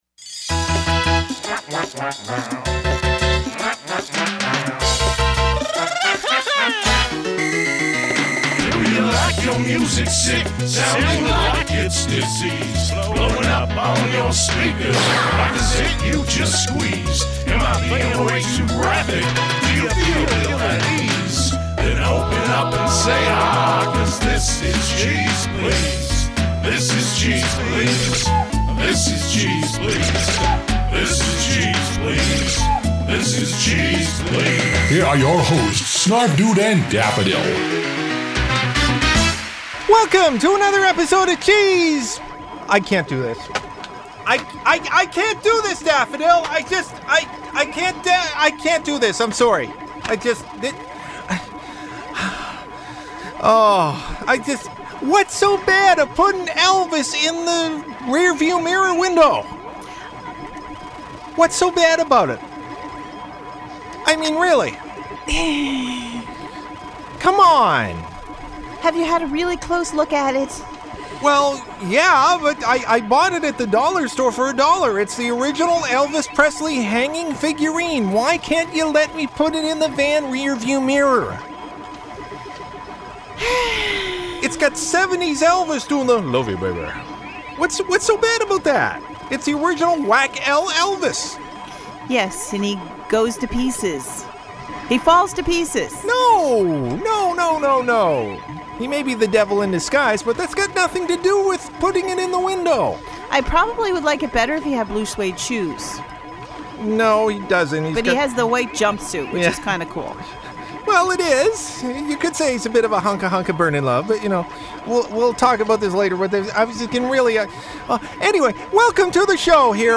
Recording Location: Somewhere on the road in a van
Arts and Culture > Comedy
192kbps Stereo